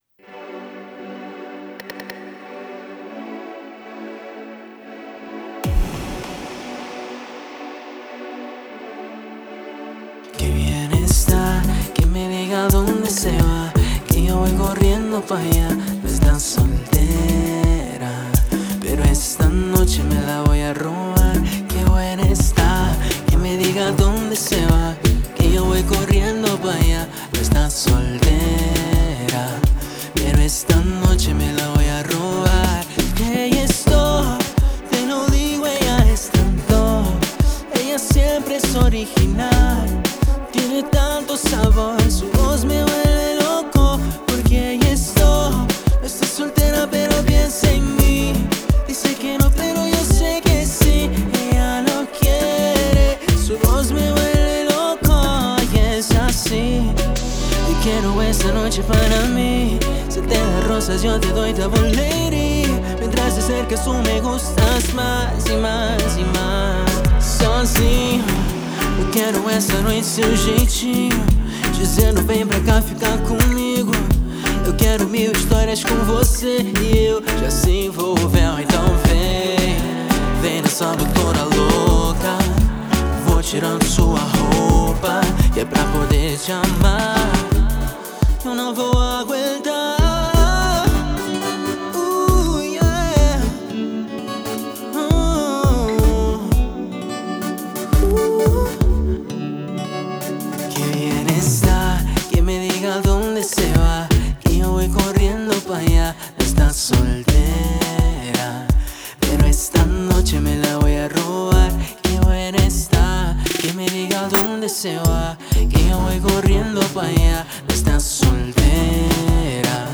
Producción musical, Composición, Fusión, Pop Latino, Orgánico, Público target, Estética